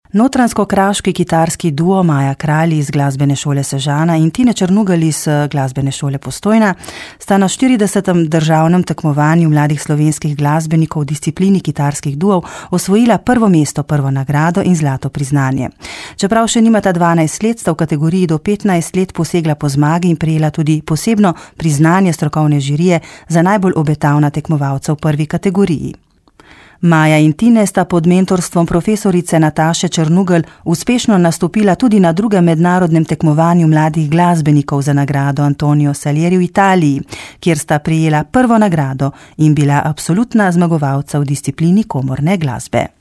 P238-45_notr-kr_kitarski_duo.mp3